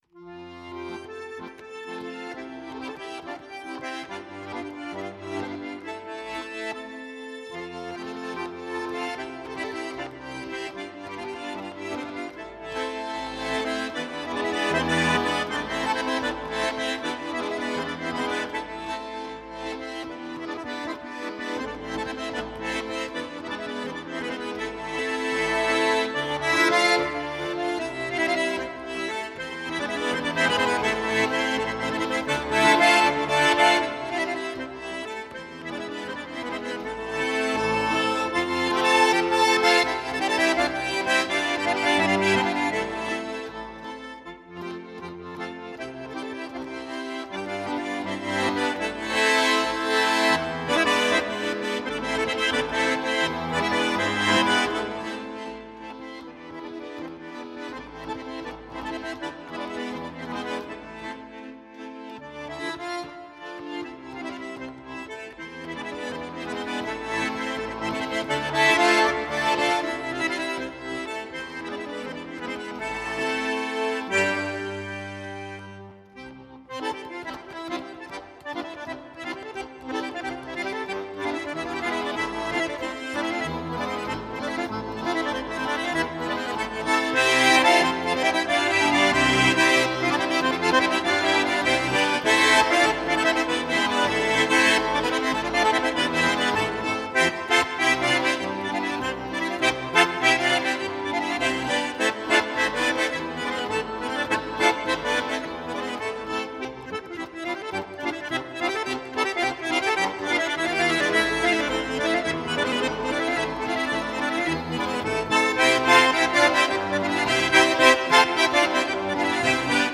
гармоника